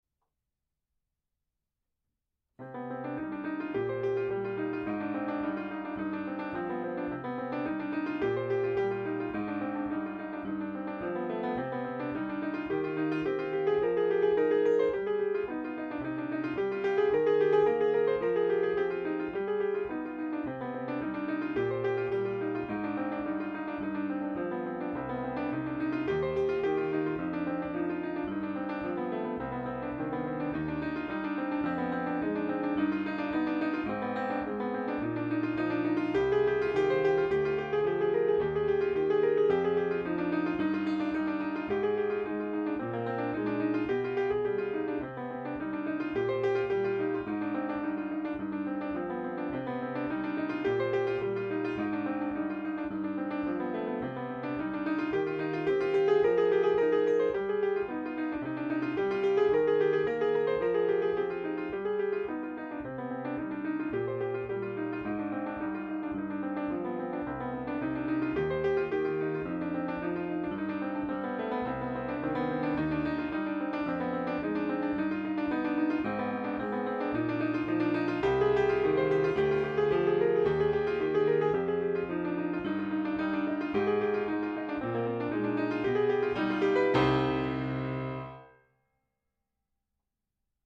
piano - classique - melancolique - nostalgique - melodique